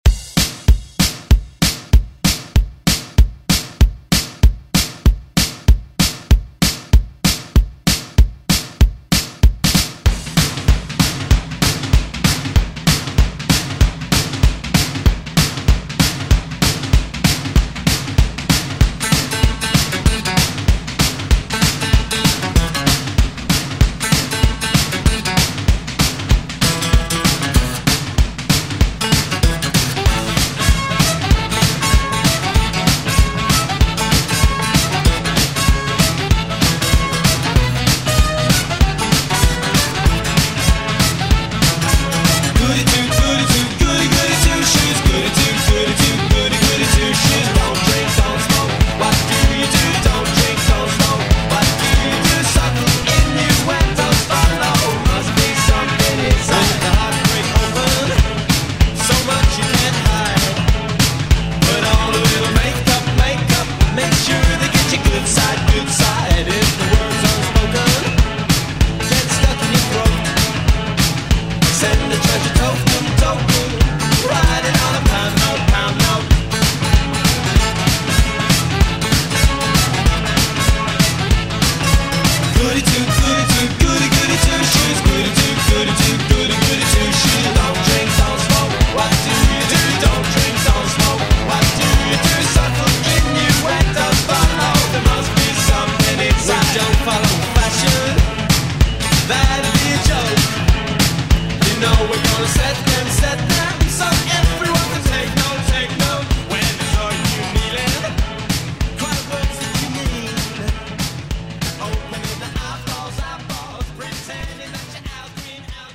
BPM: 96 Time